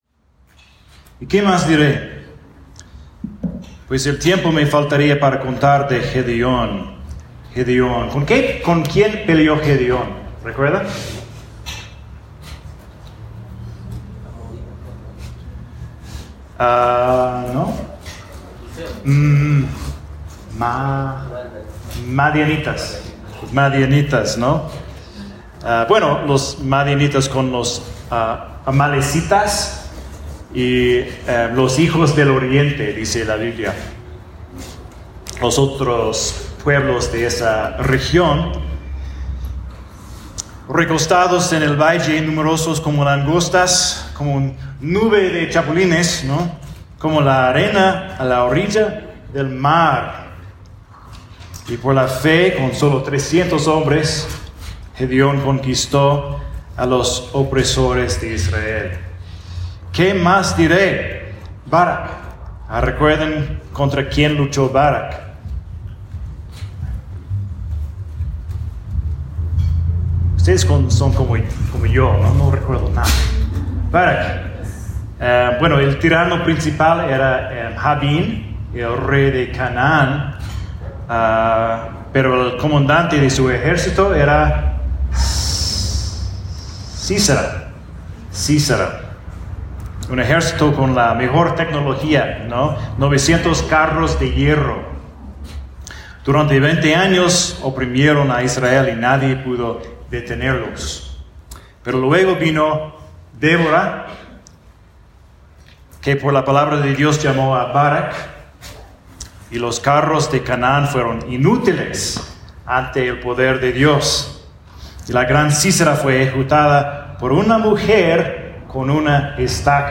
Eso es un sermón de Hebreos 11:32, y de allí al libro de Jueces.
Espero que encuentre la calidad algo mejorada.